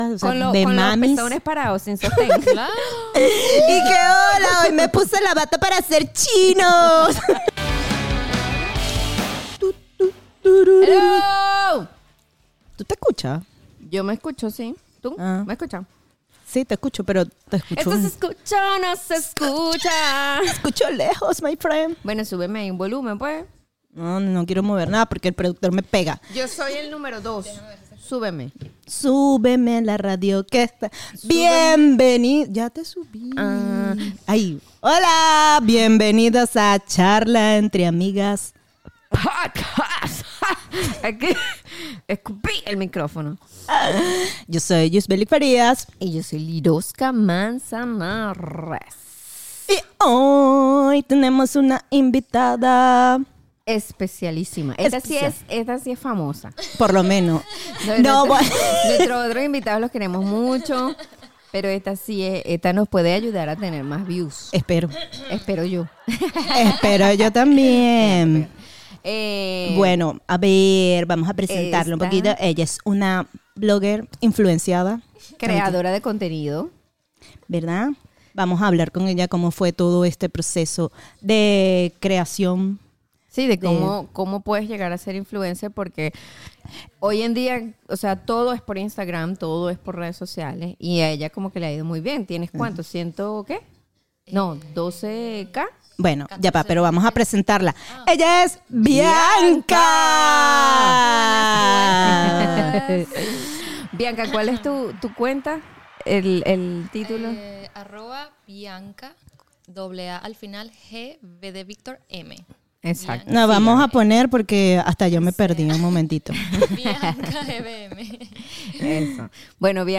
Dos amigas hablando de temas serios intentando darle una perspectiva distinta esperando que esto ayude a otra gente y lo mas importante ayudarse a ellas mismas